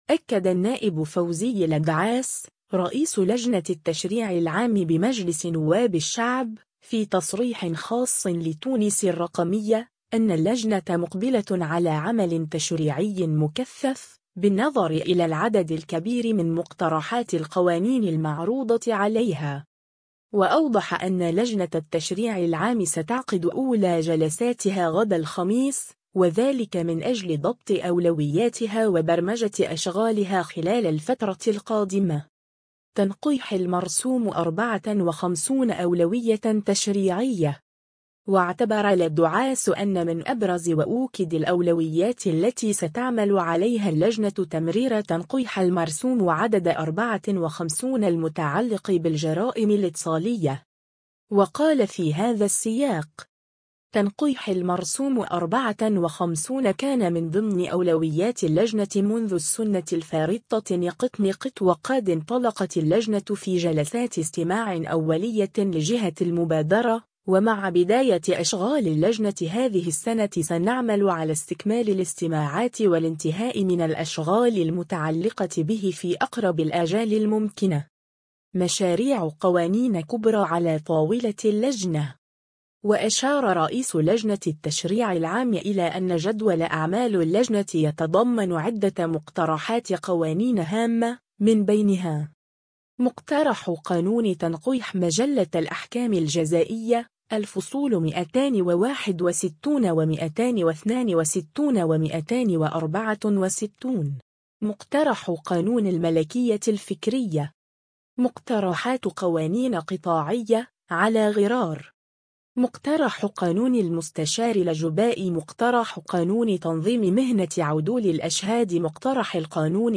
أكد النائب فوزي الدعاس، رئيس لجنة التشريع العام بمجلس نواب الشعب، في تصريح خاص لـ”تونس الرقمية”، أن اللجنة مُقبلة على عمل تشريعي مكثف، بالنظر إلى العدد الكبير من مقترحات القوانين المعروضة عليها.